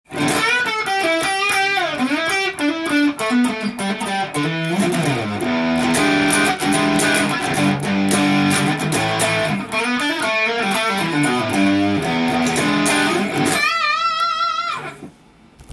歪ませてハムバッカーで弾いてみました。
音の伸びも良好でチョーキングした時の倍音もキーンという感じで
ハードロックのギターソロもいけそうですね！